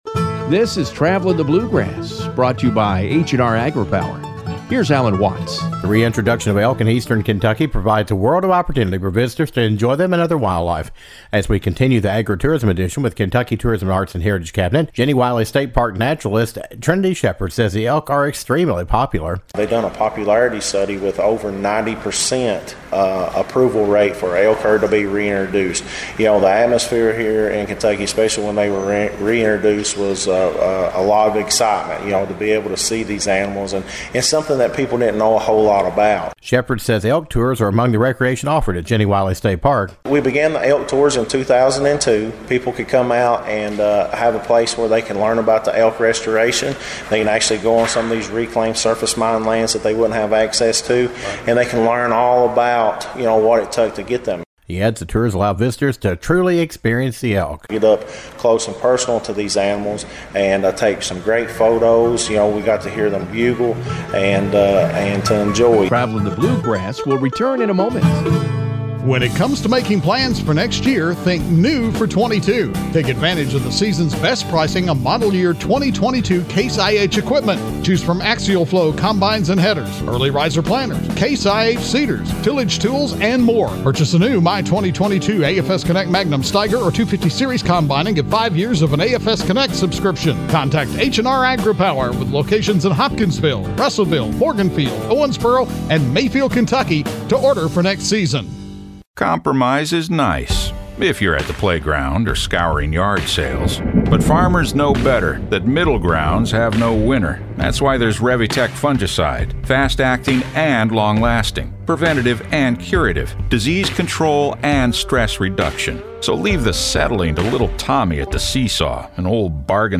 and provides an elk bugle demonstration.